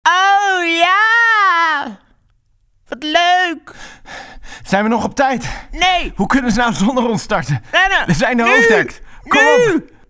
Male
TEENS, 20s, 30s, 40s
Bright, Character, Cheeky, Children, Confident, Corporate, Engaging, Friendly, Natural, Posh, Streetwise, Versatile, Young
ABN, Rotterdams, Amsterdams, Haags, Utrechts, Bavarian, Austrian, Swiss, HochDeutsch
Voice reels
Microphone: Sennheiser MK-8